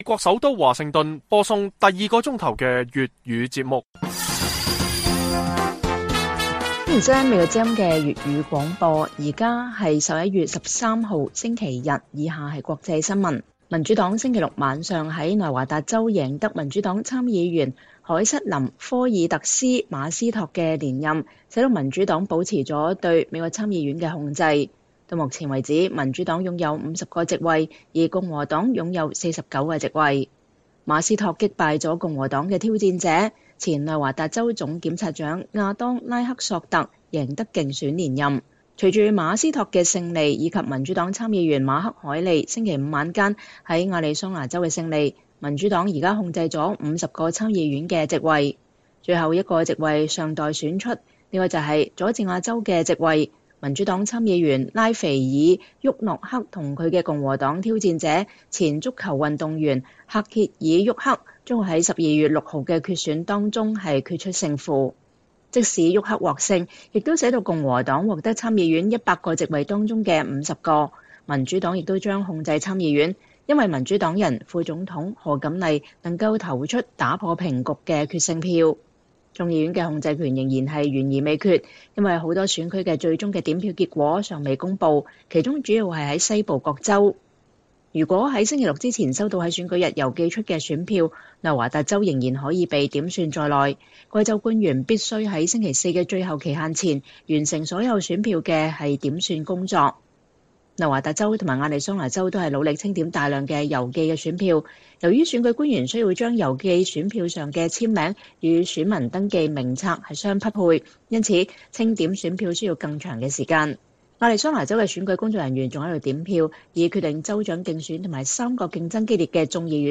粵語新聞 晚上10-11點: 拜習會：冷戰2.0後首次大國峰會？